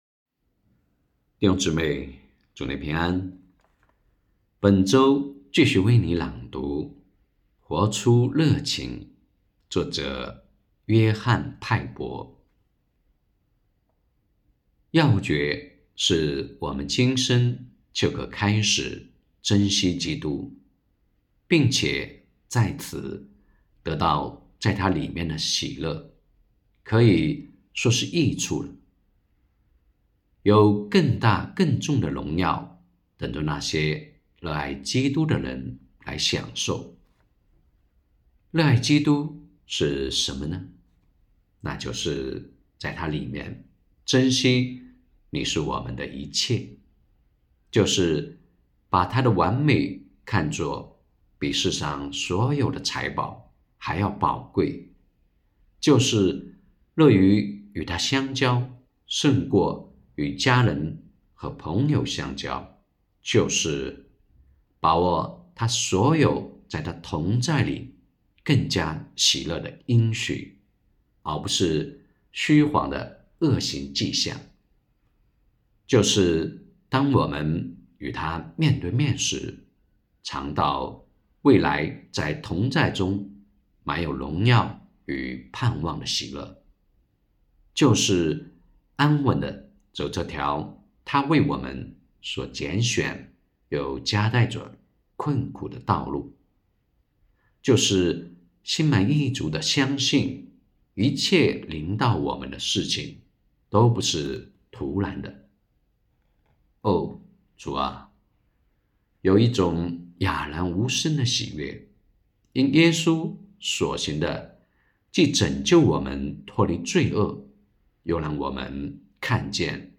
2024年11月7日 “伴你读书”，正在为您朗读：《活出热情》 欢迎点击下方音频聆听朗读内容 音频 https